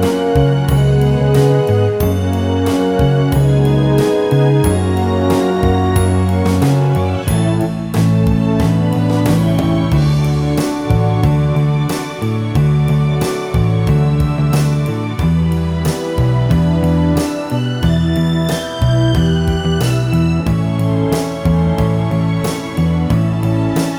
No Backing Vocals Crooners 3:20 Buy £1.50